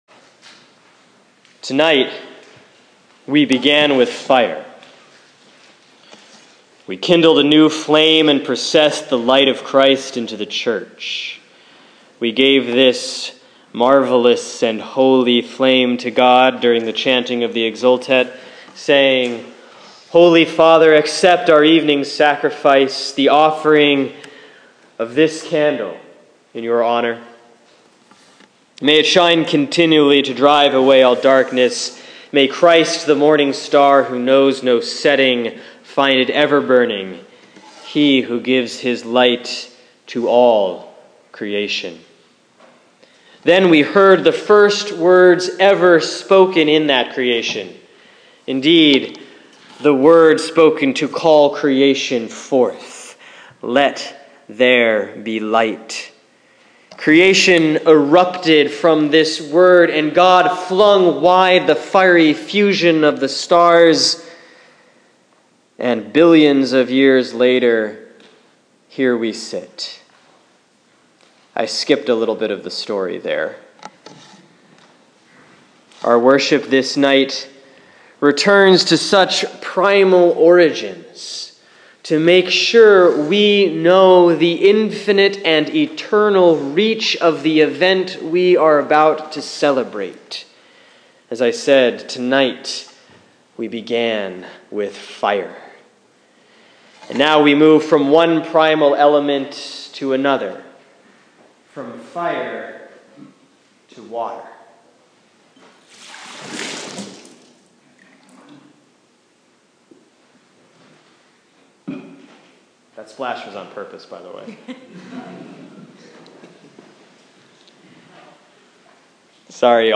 Sermon for the Easter Vigil, Saturday, April 4, 2015